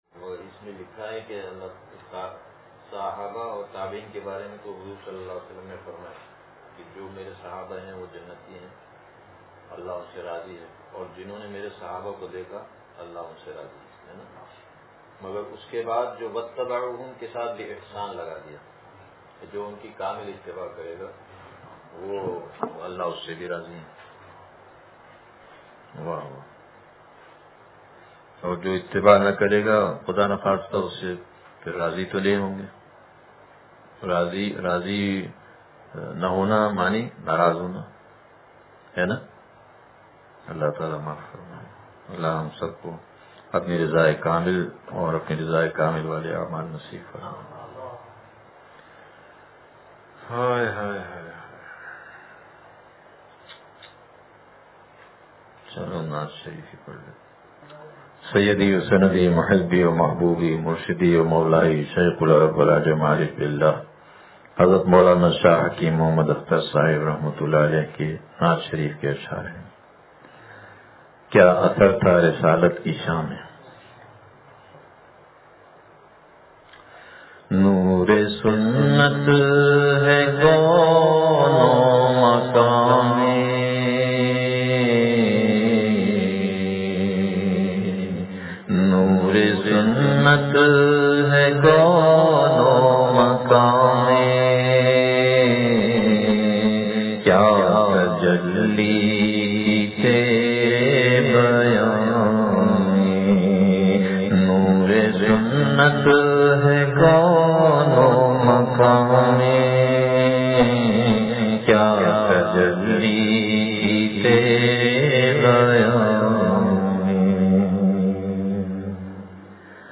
کیا اثر تھا رسالت کی شاں میں – مجلس بروز جمعرات – دنیا کی حقیقت – نشر الطیب فی ذکر النبی الحبیب صلی اللہ علیہ وسلم